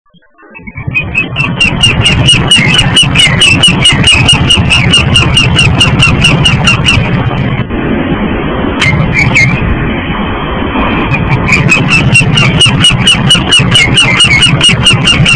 15.05.05 Bärenbach - Gewann Mittlere Halde
Wendehals, 1/-,
Wendehals-a.mp3